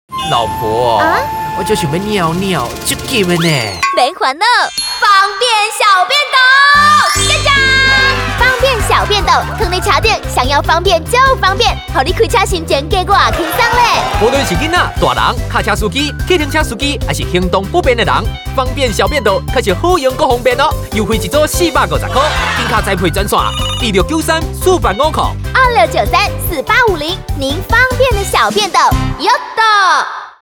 台語配音 國語配音 男性配音員
他擁有多變且廣闊的音域，從青春洋溢的少年代角色到沉穩成熟的中年人物皆能詮釋自如。